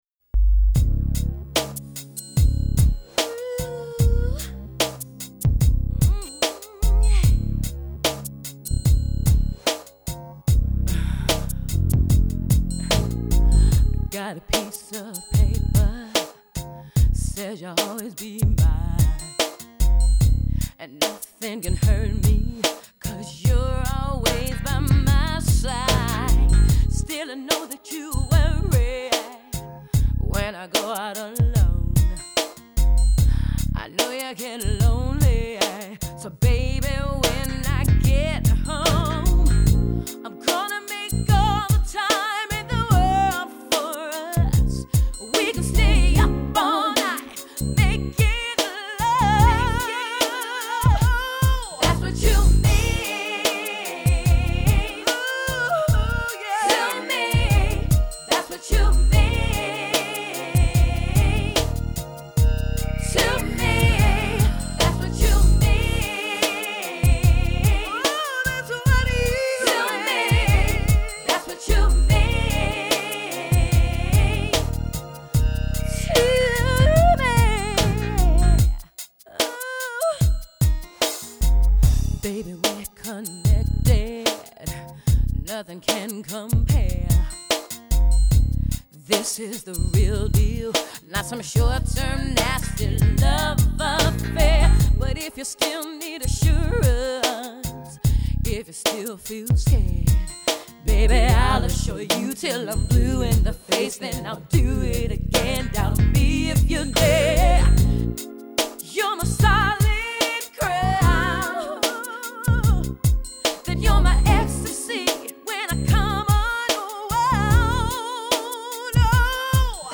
Female 1990's style R & B Pop